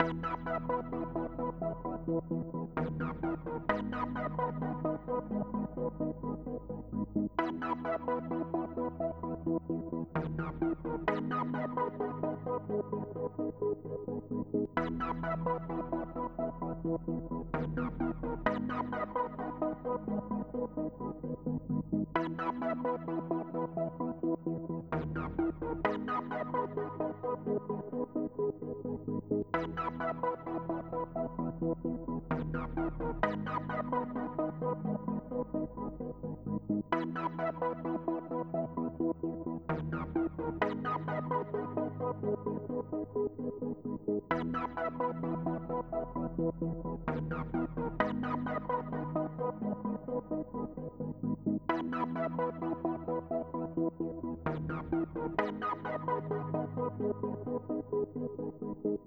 Futurish Pad.wav